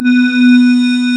M1 GLASS  B3.wav